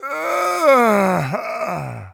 die1.ogg